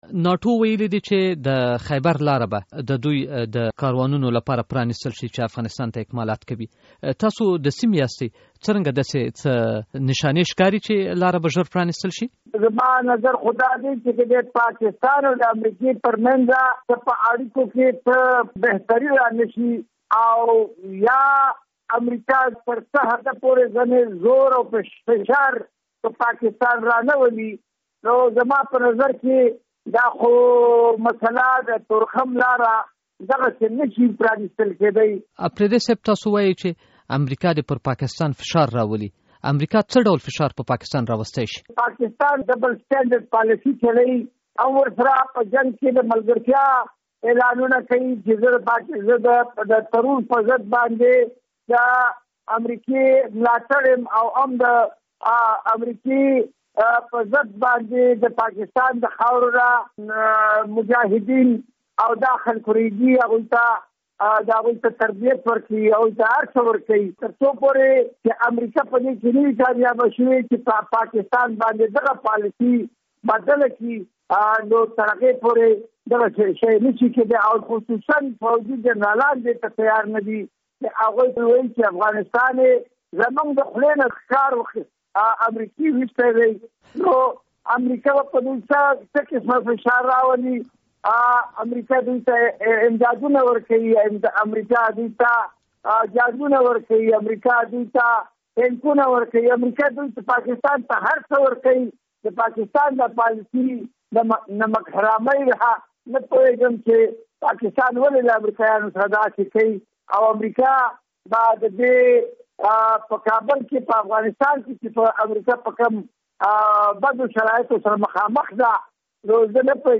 لطیف اپریدي سره مرکه